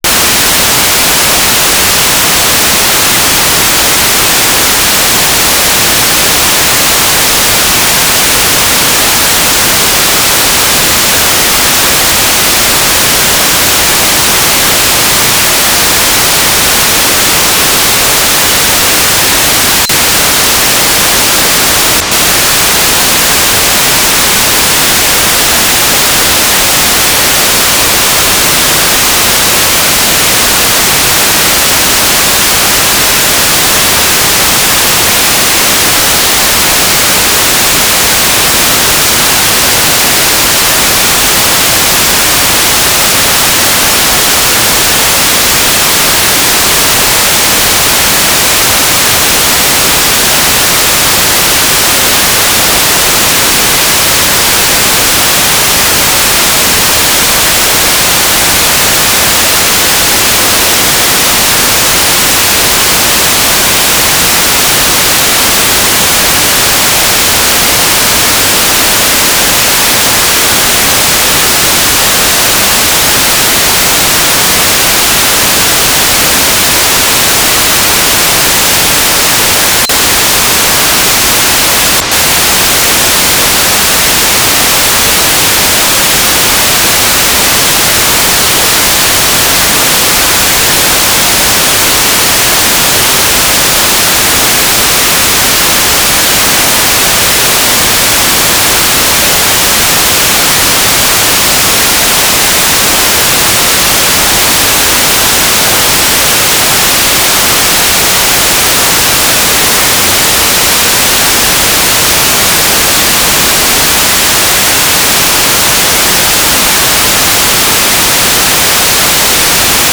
"transmitter_description": "UHF Unknown",
"transmitter_mode": "FM",